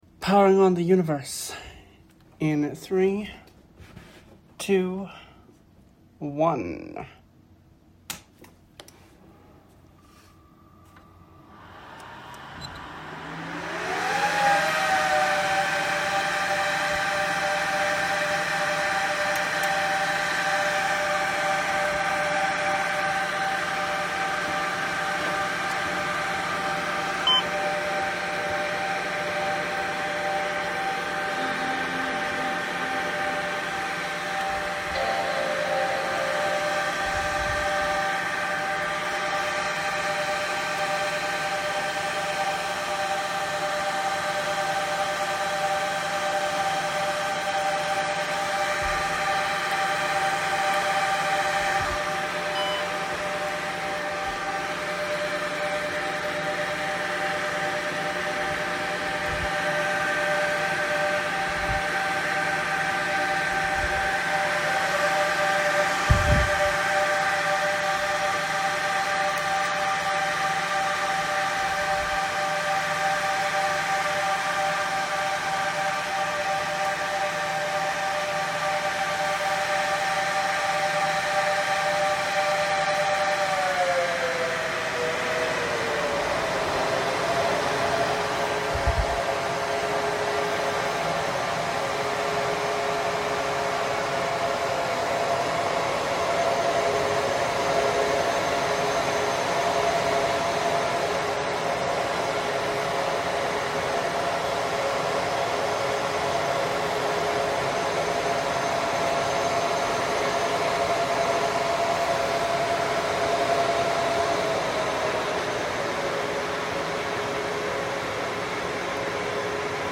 many many many many many servers. actually if you're curious here's what it sounds like to boot up all the bastards at once.